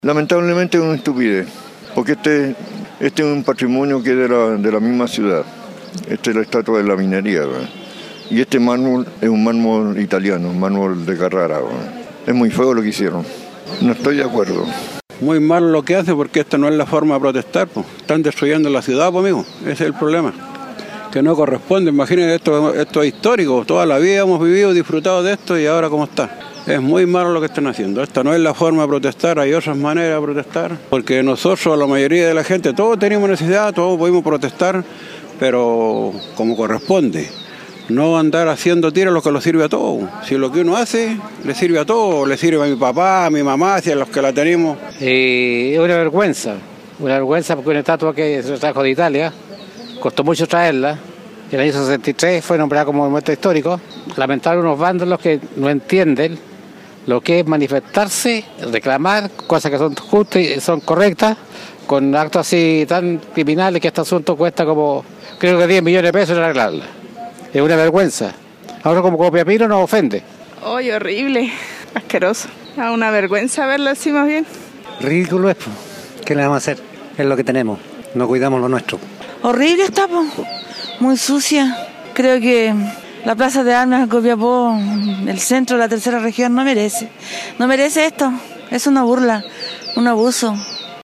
Consultamos a las personas que estaban en las cercanías de esta escultura y estas fueron sus palabras ante actual estado del monumento: